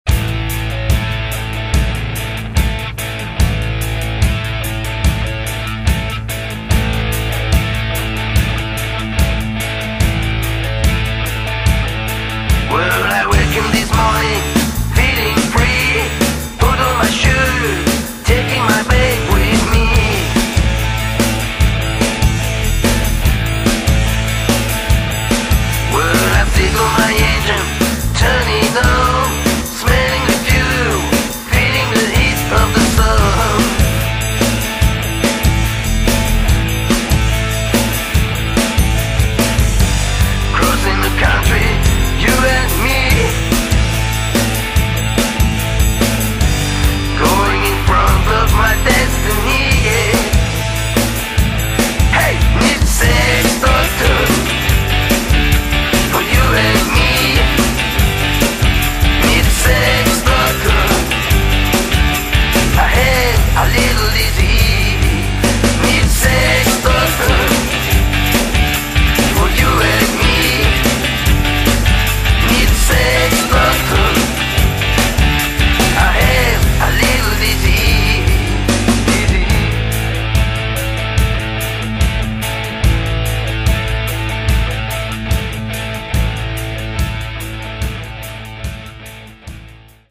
Enregistrement Studio BBM Lausanne